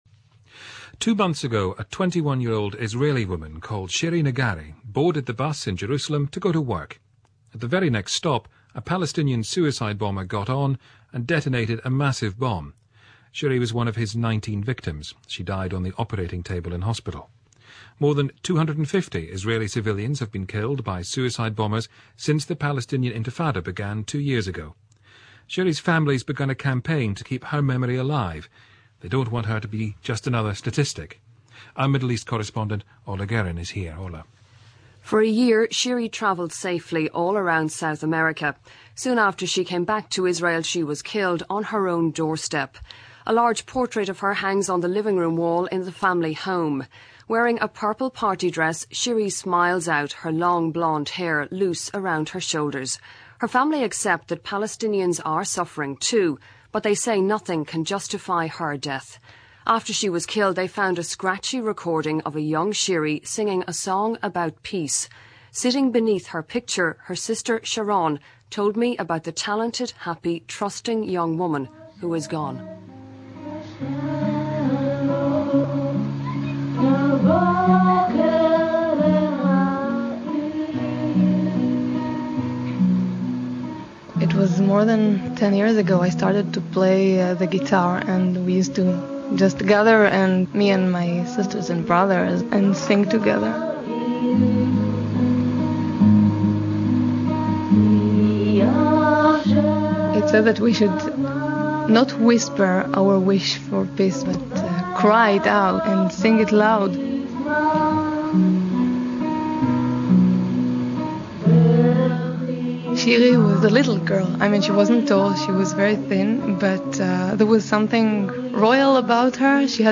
a BBC interview